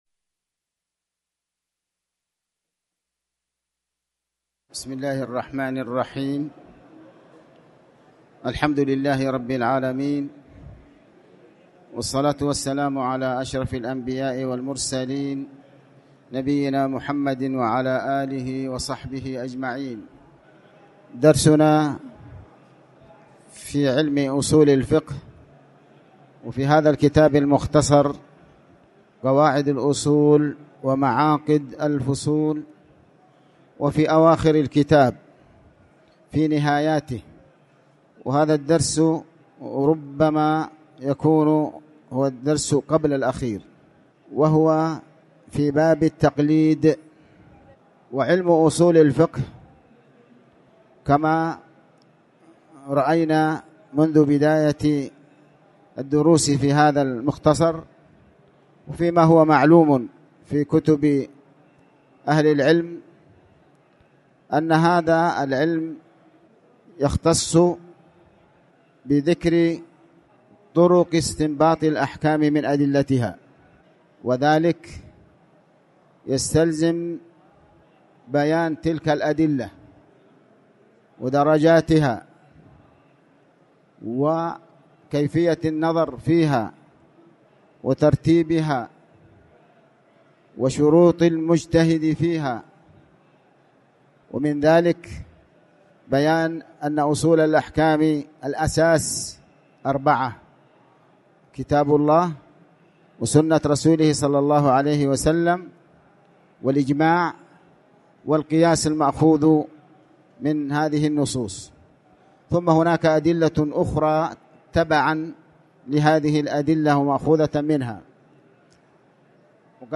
تاريخ النشر ١٠ ذو القعدة ١٤٣٨ هـ المكان: المسجد الحرام الشيخ: علي بن عباس الحكمي علي بن عباس الحكمي باب التقليد